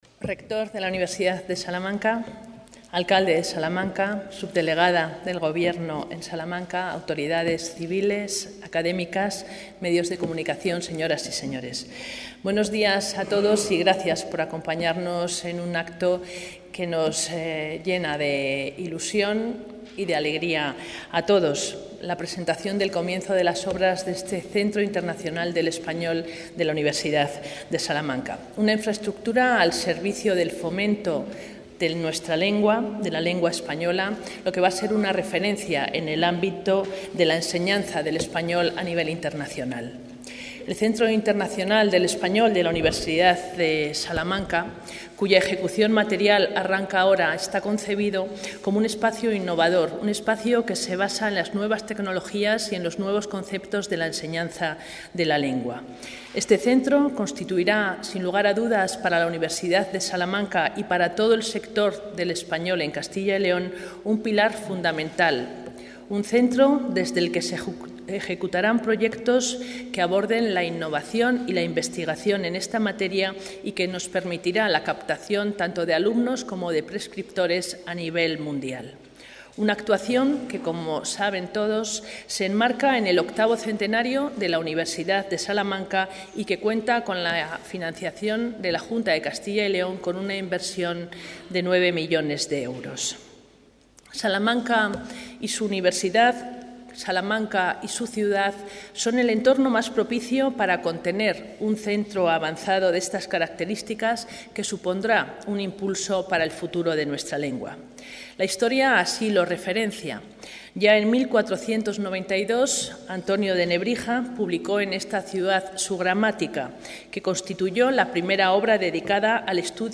Intervención de la consejera de Cultura y Turismo.